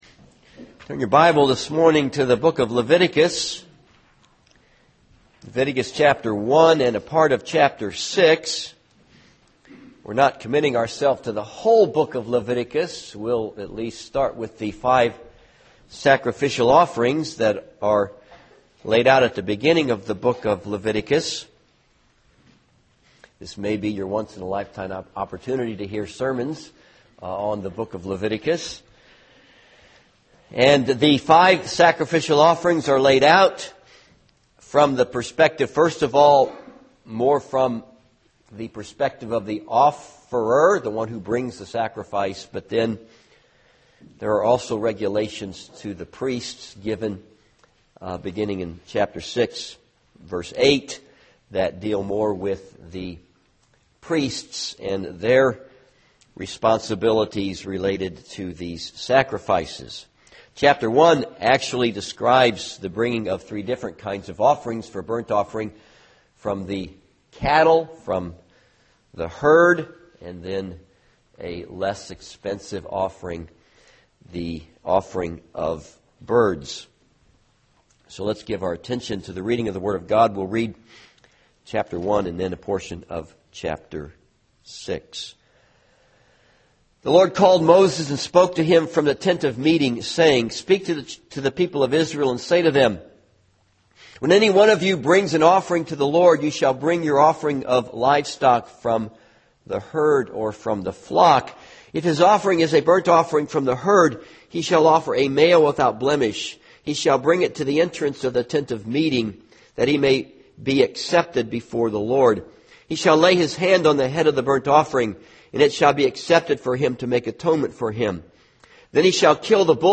This sermon is based on Leviticus 1 and Leviticus 6:8-13.